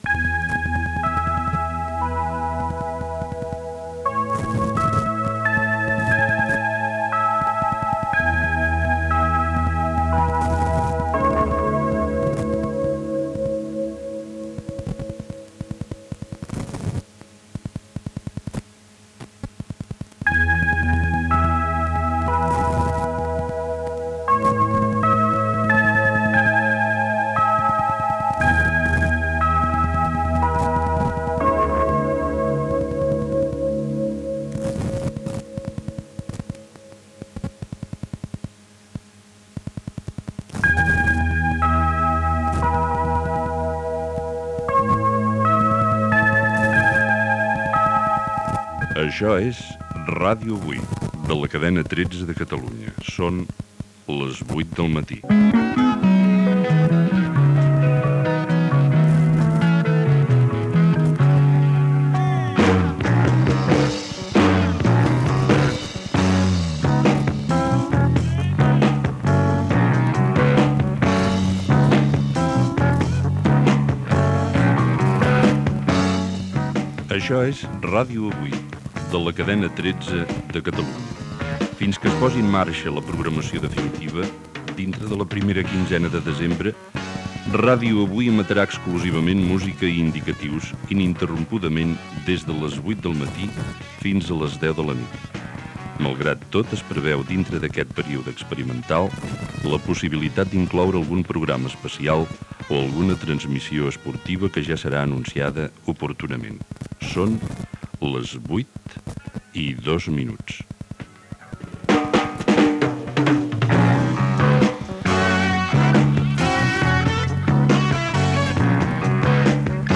Primer dia d'emissió en proves. Identificació i emissores que formen la Cadena 13 inicialment.